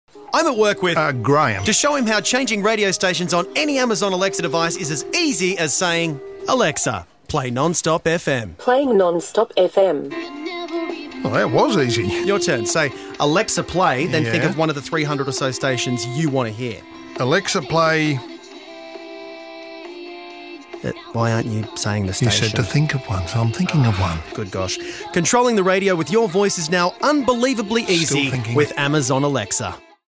The new Alexa skill will be promoted across commercial radio stations nationally. Each ad features a man called ‘Graeme’ working out how to use the skill.